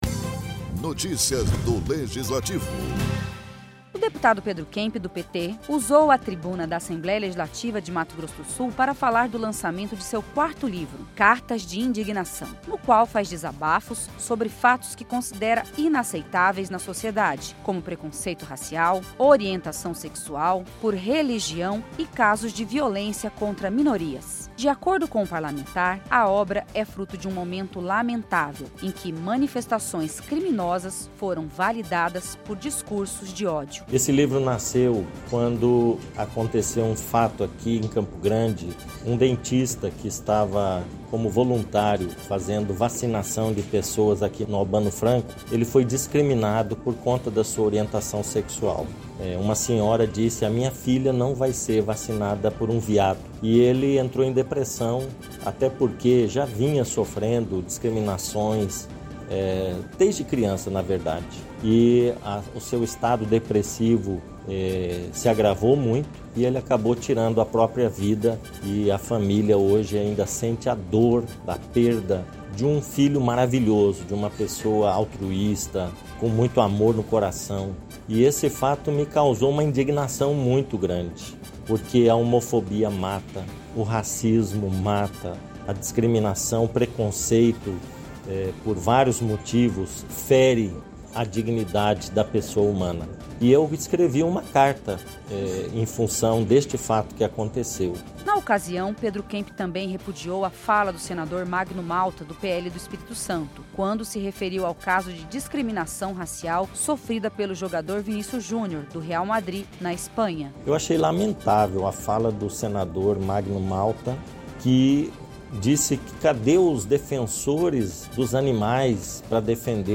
Deputado Pedro Kemp do PT usou a tribuna da Assembleia Legislativa de Mato Grosso do Sul para falar do lançamento de seu quarto livro “Cartas de Indignação”, que será lançado no Festival Literário de Bonito e trata de casos de preconceito racial, por orientação sexual, religião e casos de violência.